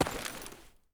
dc0f4c9042 Divergent / mods / Soundscape Overhaul / gamedata / sounds / material / human / step / gravel2.ogg 34 KiB (Stored with Git LFS) Raw History
gravel2.ogg